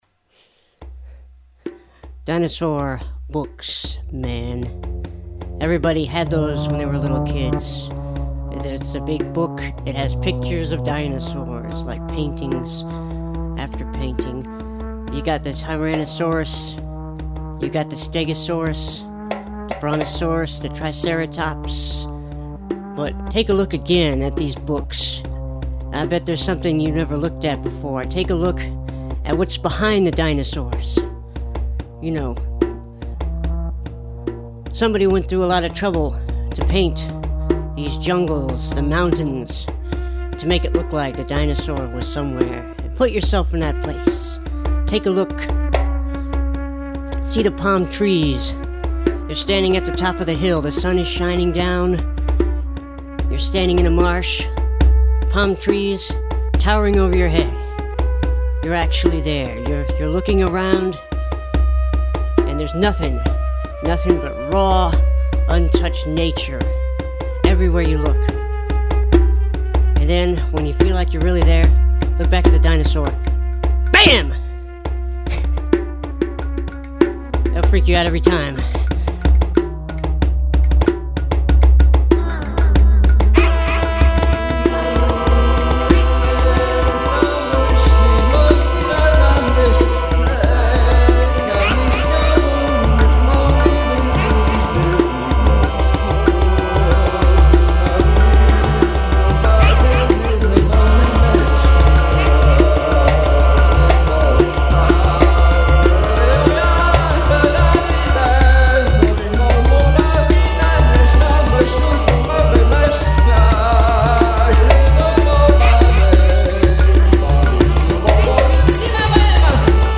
This is a classic beat/skit track, the background is pure chaos.